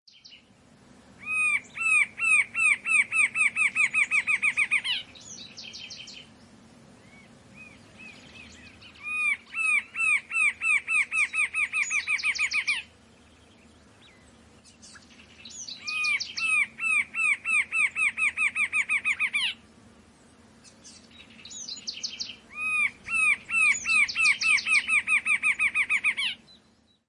Rufous-capped Antshrike (Thamnophilus ruficapillus)
Sex: Both
Life Stage: Adult
Country: Uruguay
Location or protected area: Minas de Corrales
Condition: Wild
Certainty: Observed, Recorded vocal
Thamnophilus-ruficapillus-en-Minas-de-Corrales.mp3